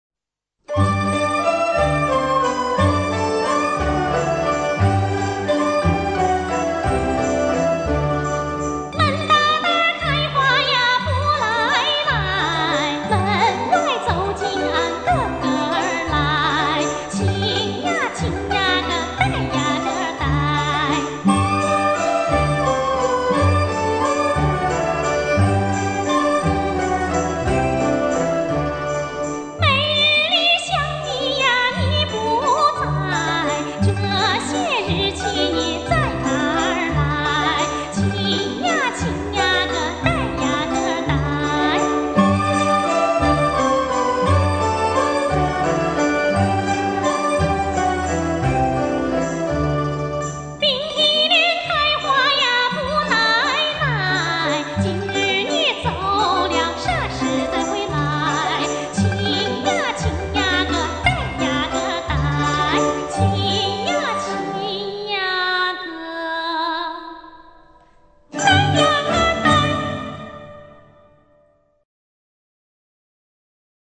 山西左权民歌